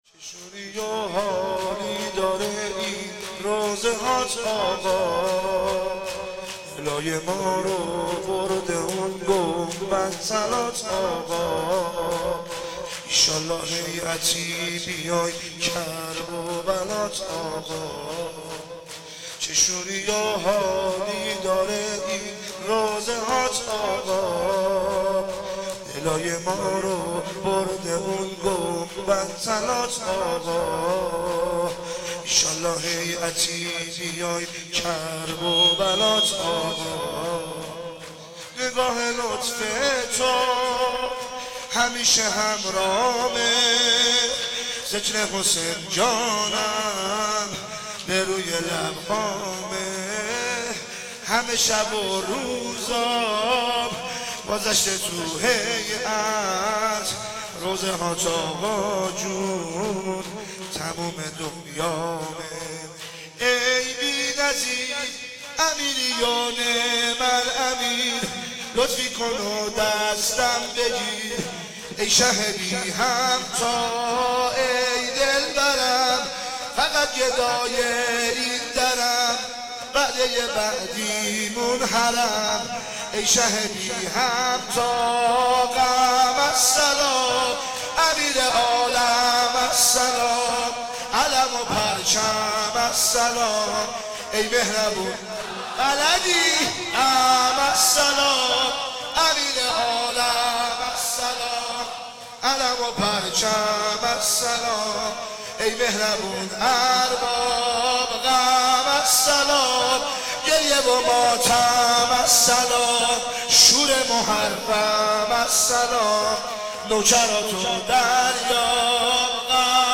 شب پنجم محرم 1395